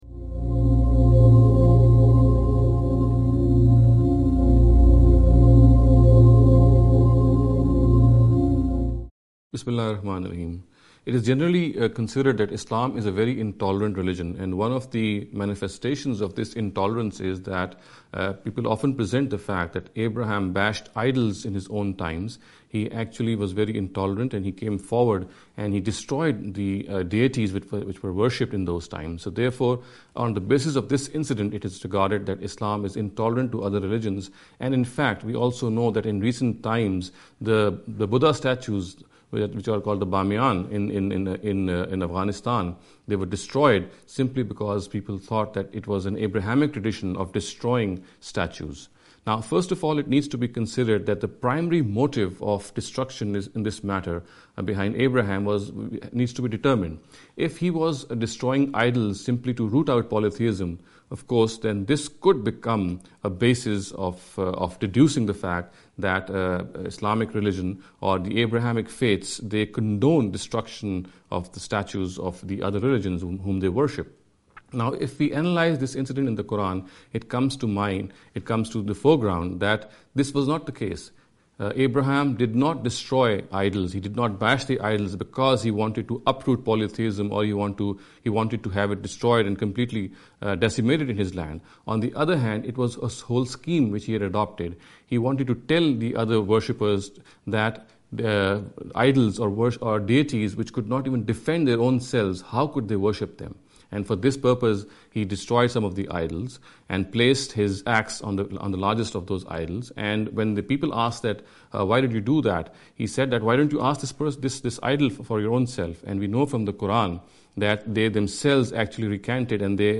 This lecture series will deal with some misconception regarding the Islam and Non-Muslims.